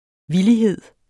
Udtale [ ˈviliˌheðˀ ]